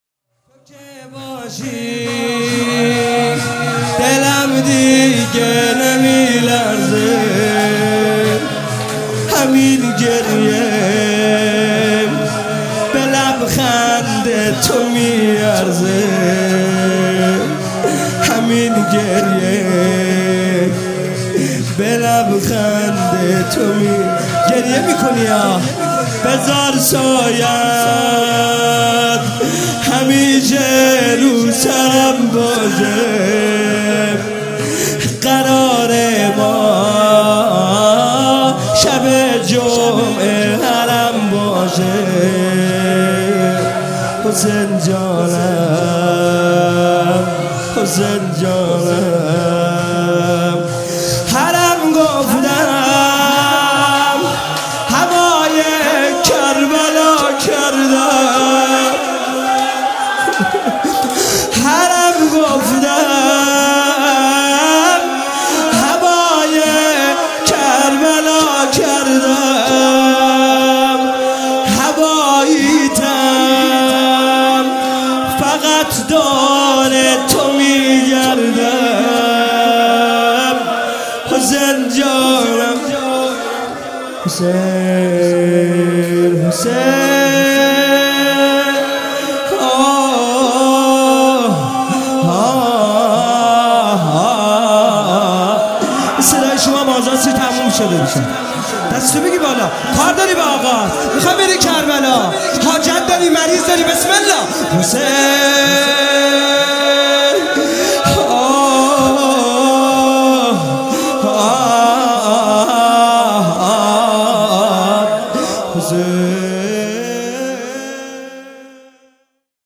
فاطمیه دوم 95 - شب 3 - شور - تو که باشی دلم دیگه نمیلرزه